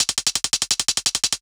UHH_ElectroHatA_170-05.wav